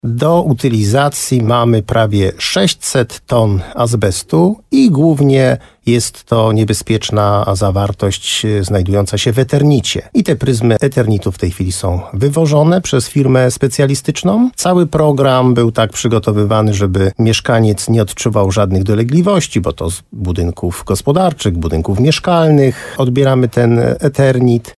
Rozmowa z Jerzym Wałęgą: Tagi: Jerzy Wałęga gmina Moszczenica Słowo za Słowo Gorlice azbest wójt